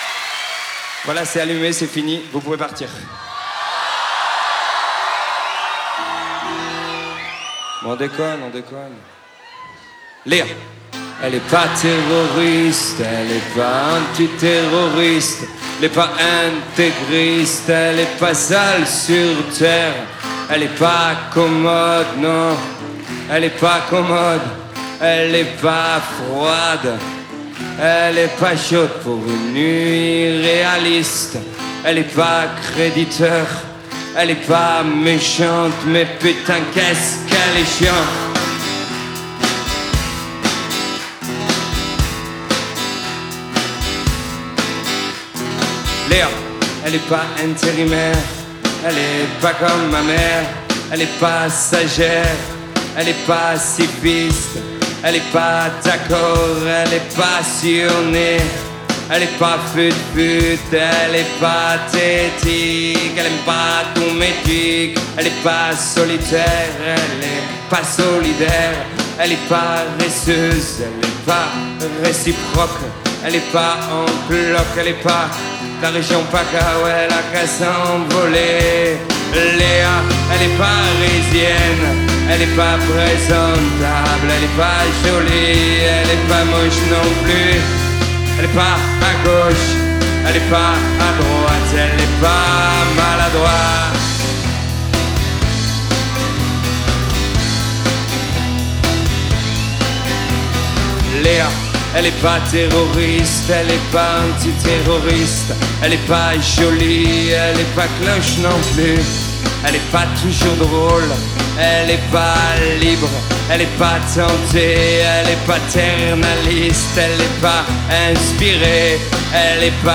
Live à la salle de la Cité, Rennes